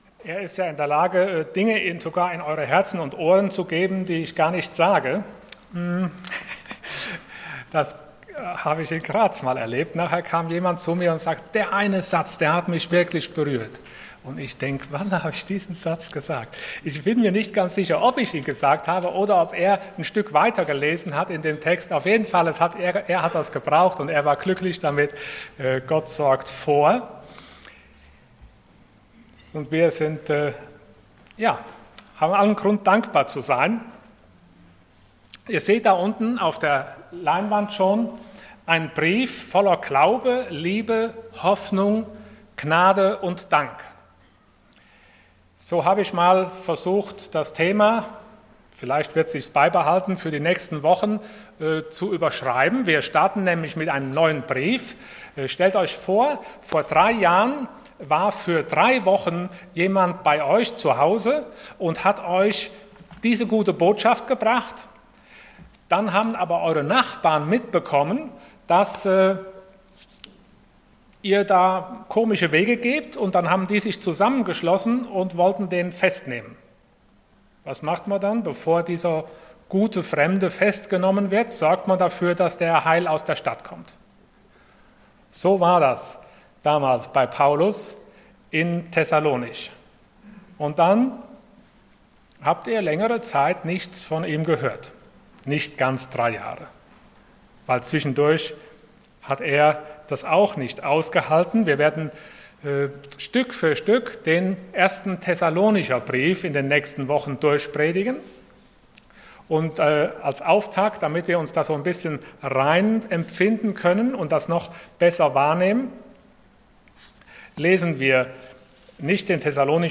Passage: 1 Thessalonians 1:1-3 Dienstart: Sonntag Morgen